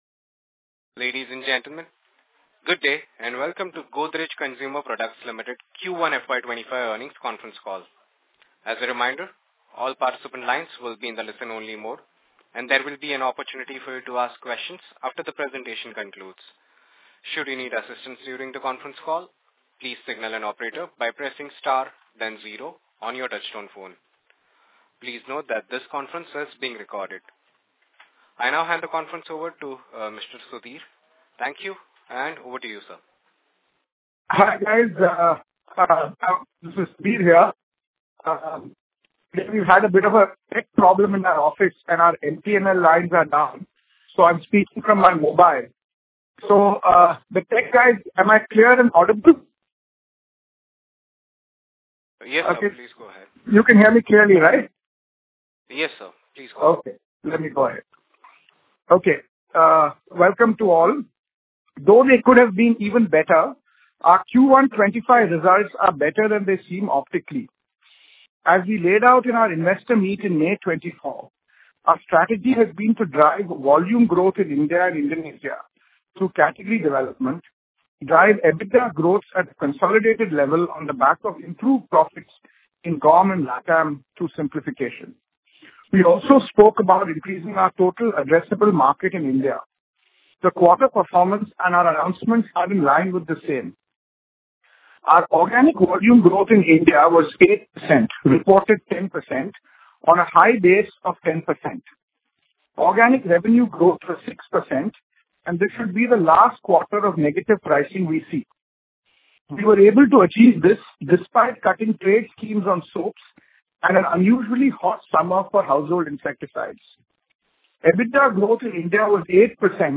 Playback of Q3FY26 Earnings Call